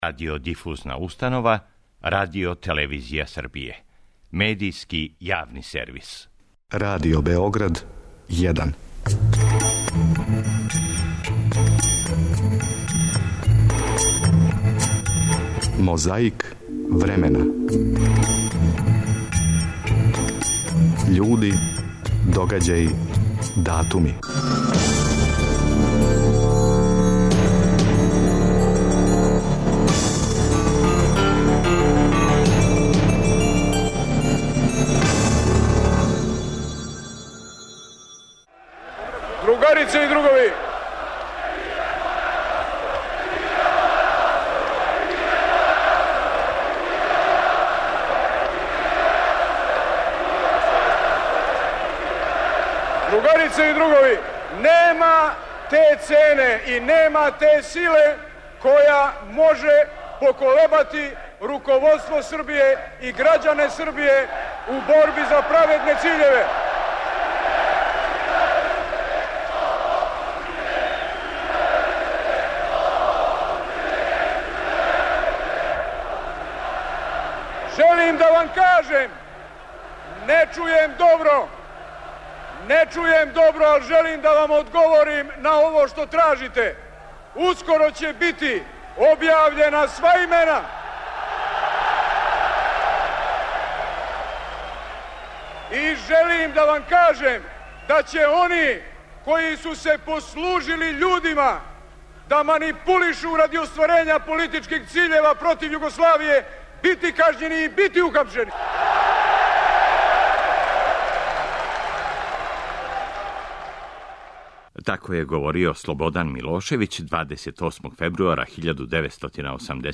Догађај: протестни скуп испред Савезне Скупштине у Београду.
Глумац Светозар Цветковић, причао је о свом животу и одрастању у емисији Радио Београда "Дете је отац човека", 23. фебруара 1994. године.